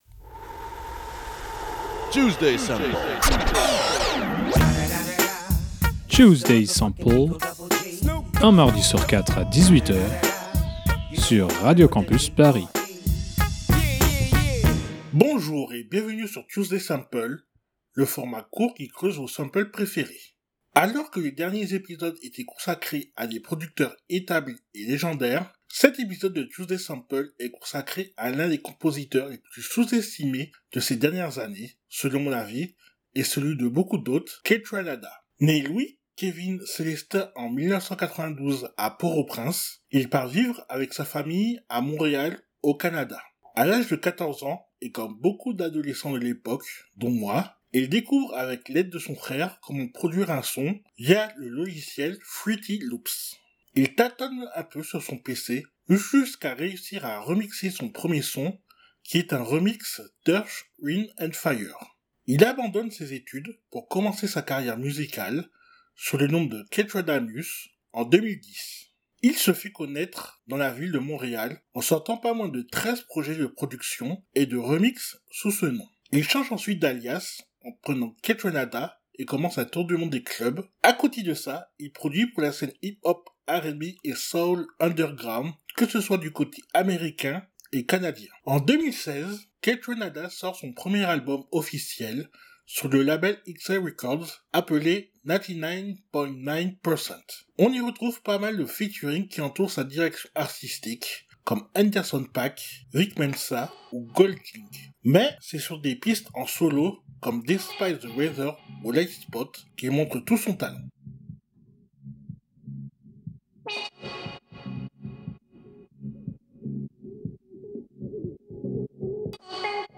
Afro Beat, Electro, Dance, Hip Hop. 4 genres musicaux qui ont bercé et inspiré la patte de Kaytranada. De ces débuts sur fruity loops à arpenter les boites de nuit dans le monde entier, Kaytranada est l'un des producteurs à suivre dans les prochaines années.